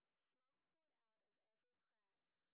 sp13_street_snr20.wav